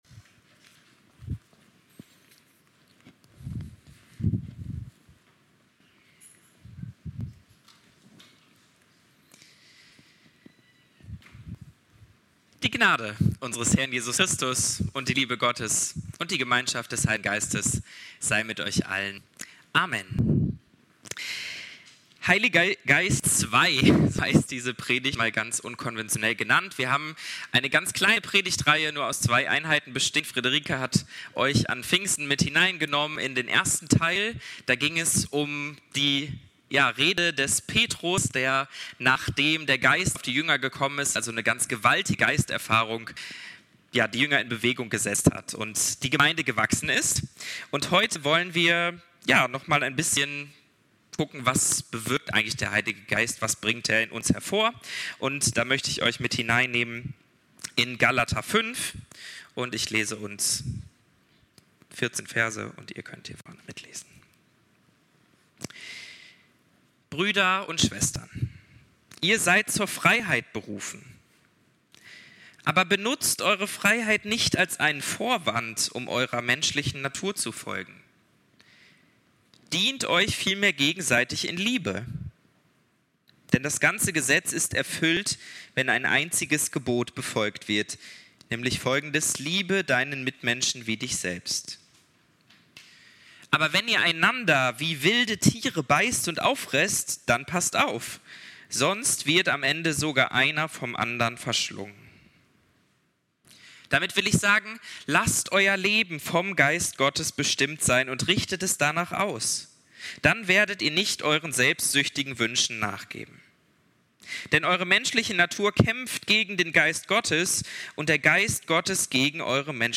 Zweiter Teil der Predigtreihe zum Heligen Geist.